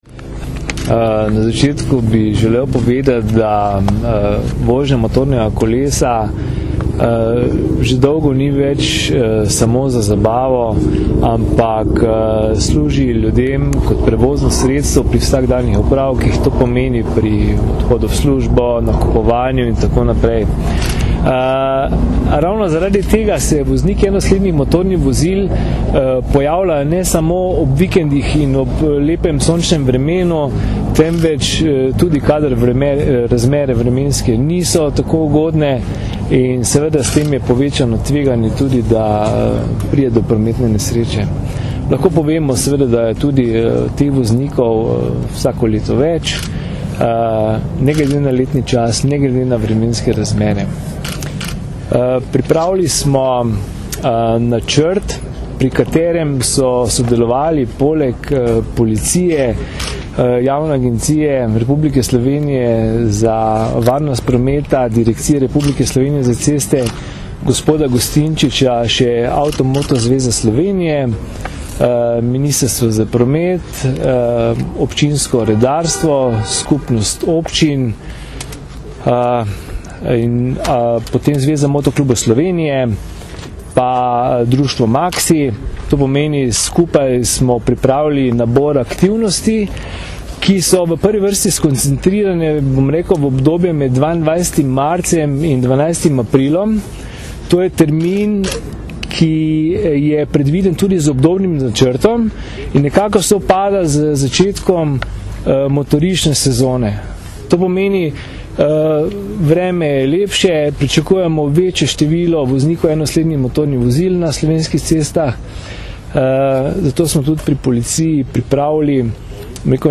Ob začetku motoristične sezone predstavili akcijo za večjo varnost motoristov - informacija z novinarske konference
izjava (mp3)